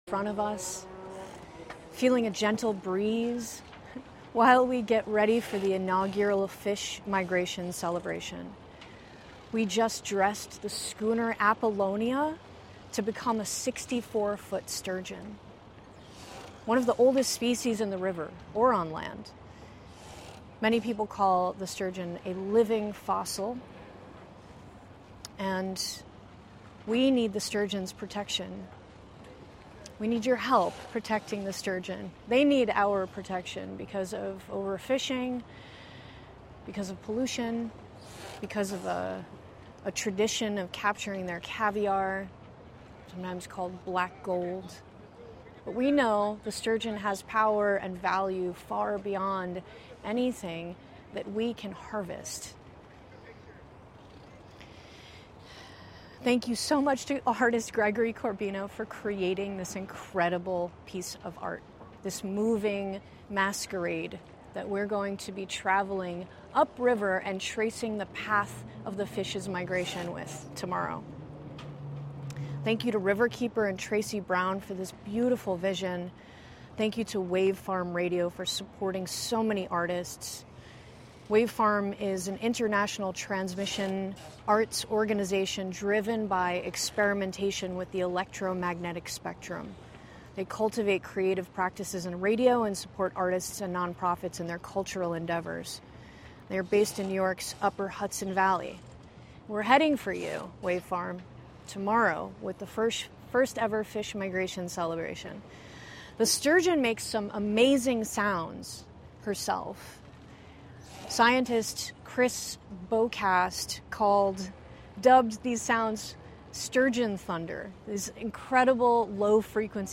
Live from Catalyst Culture Labs (International): First test broadcast (Audio)